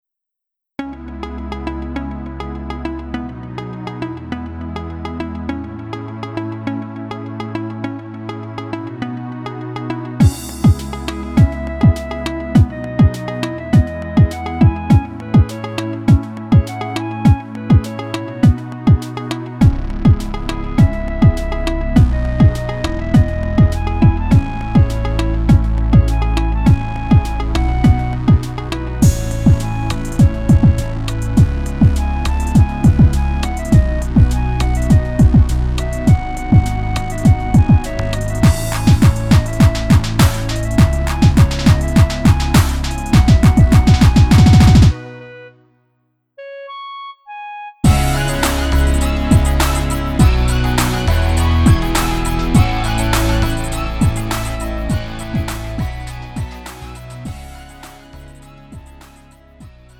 -멜로디MR 가수
음정 원키 장르 가요